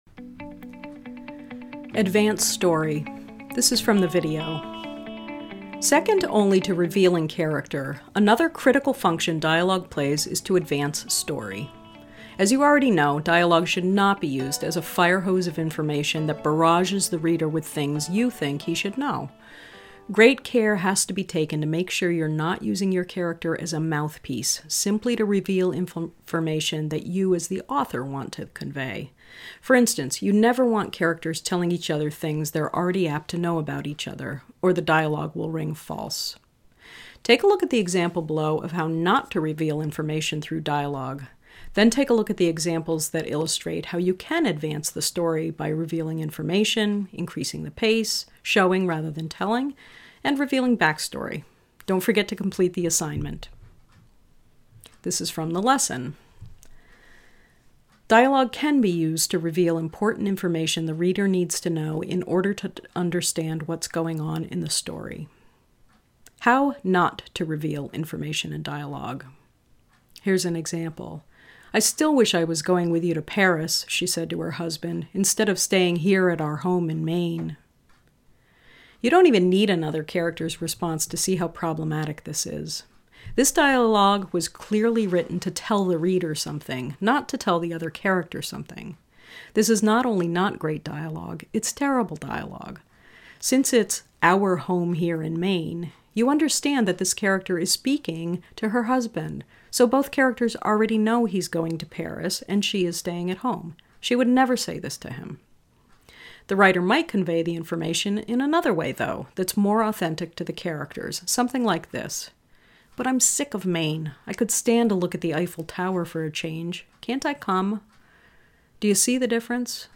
SAMPLE LESSON